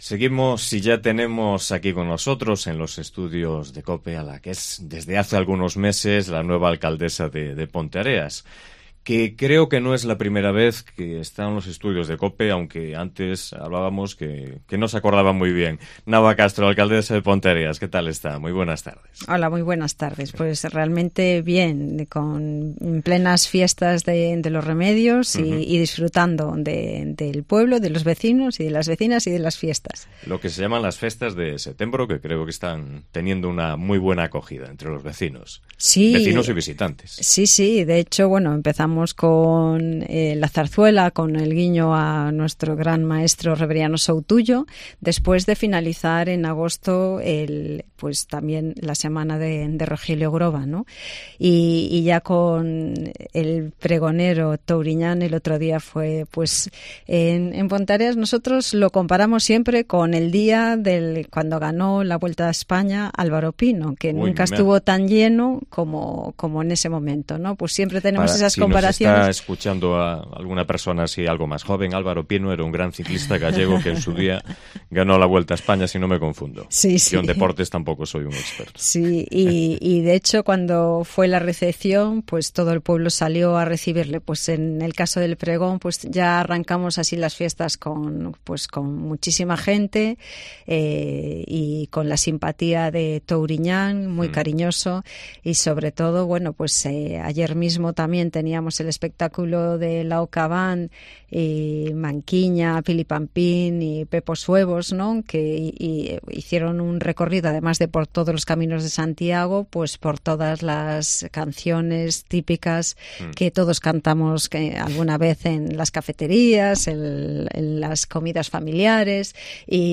Entrevista con Nava Castro, alcaldesa de Ponteareas